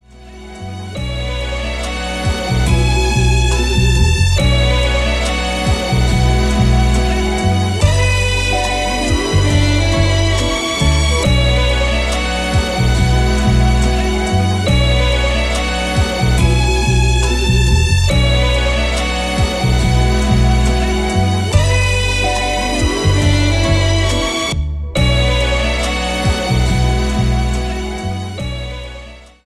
Melo Rap